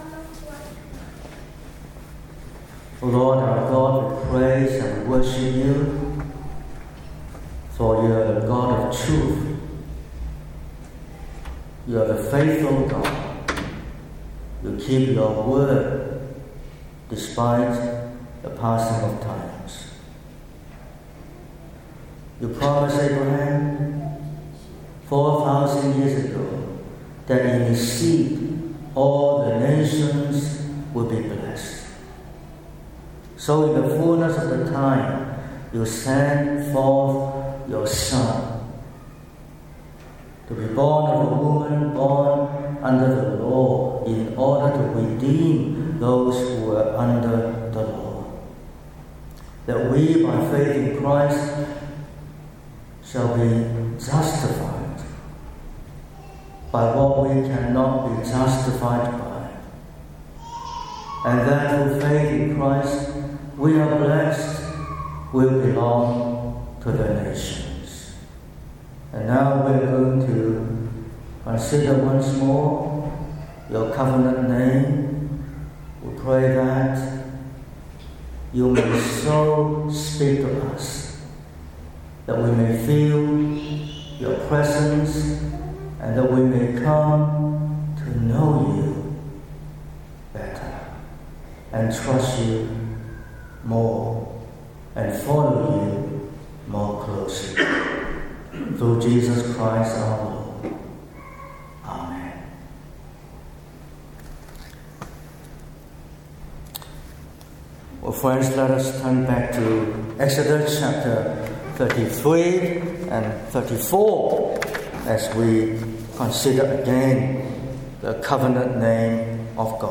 18/01/2026 – Morning Service: How can God forgive sins?